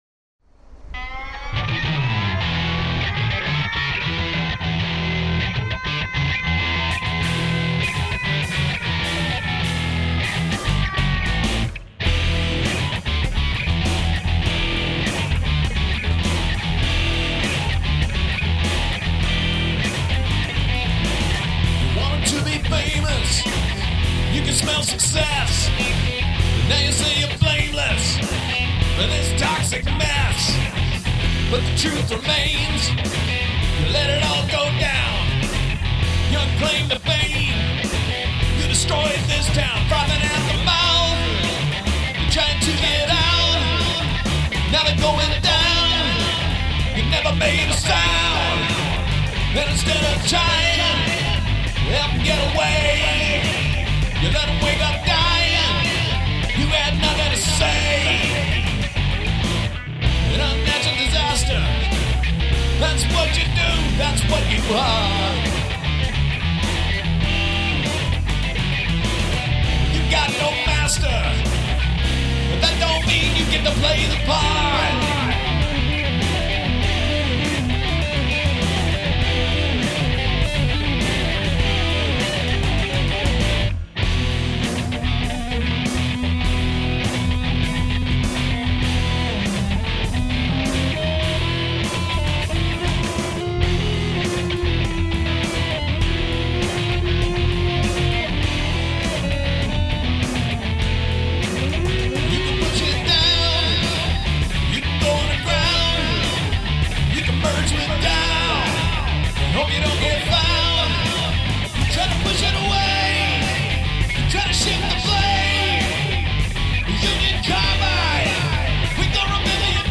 Must include prominent use of backwards recording
Funkmetal, eh? That guitar tone is over-the-top.